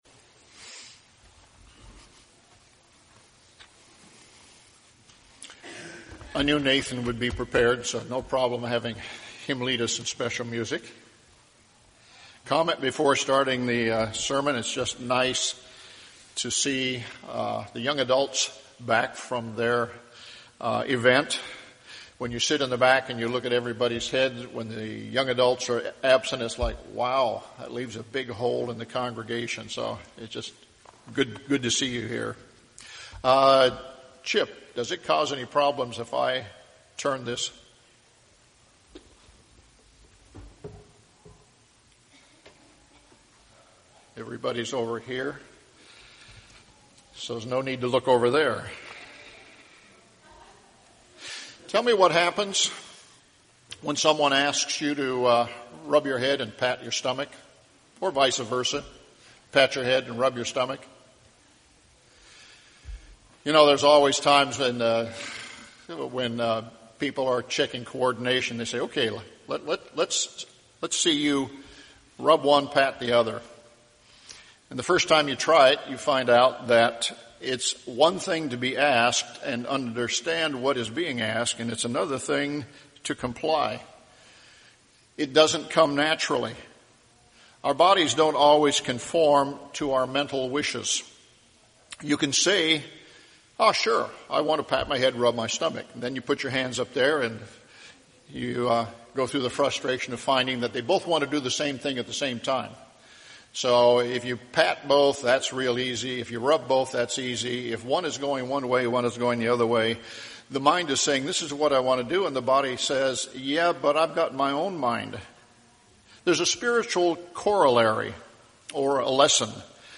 This sermon shows the constant struggles we all face in this life.